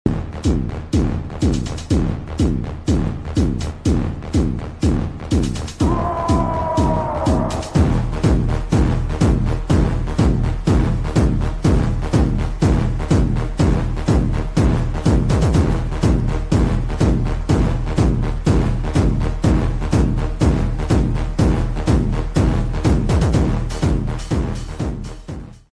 slowed+revereb